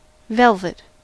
Ääntäminen
IPA : /ˈvɛlvɪt/